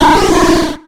Cri de Simularbre dans Pokémon X et Y.